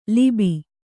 ♪ libi